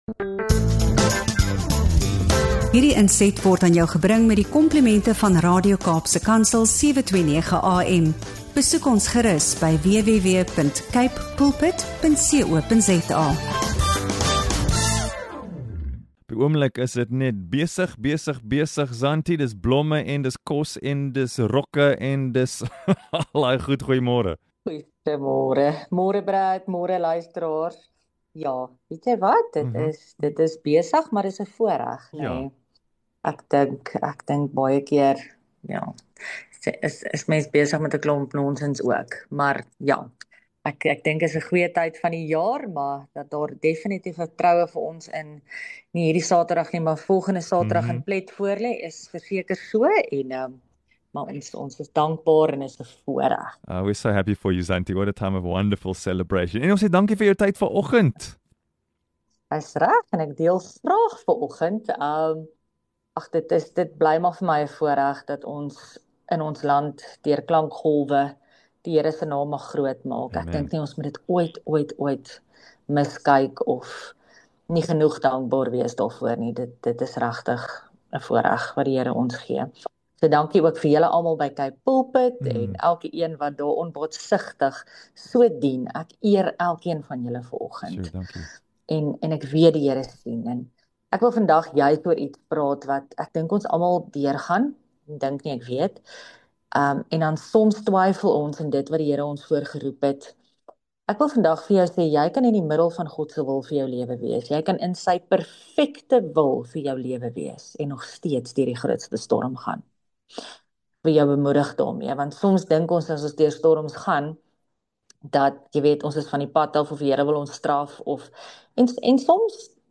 This powerful message offers a profound correction: you can be in the center of God’s perfect will and still face life’s most violent tempests. Using the dramatic story of the Apostle Paul’s shipwreck in Acts 27-28, the speaker reveals that faith is not positive thinking or smooth sailing—it’s clinging to a specific word from God.